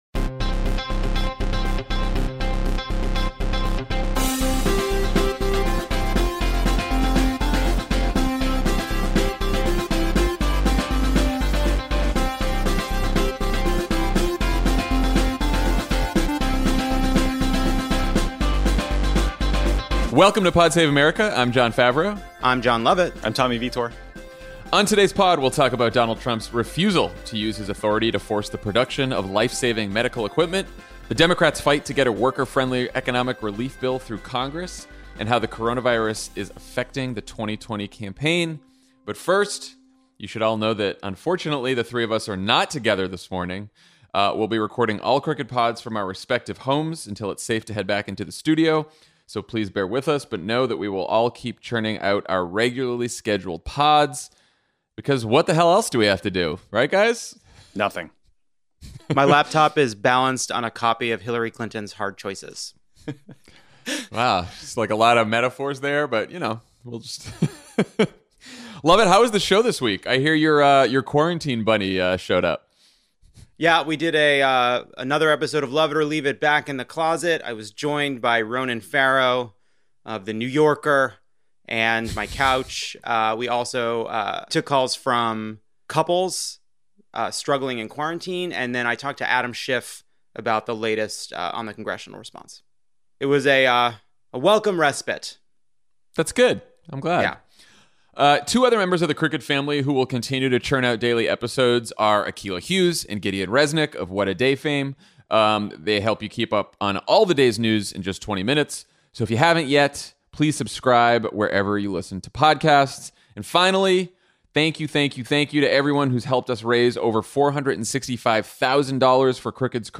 Trump refuses to force the production of life-saving medical equipment, Democrats fight to eliminate a corporate slush fund from Mitch McConnell’s stimulus bill, and the coronavirus pandemic upends the 2020 campaign. Then, health care workers share stories about how they’re grappling with this crisis.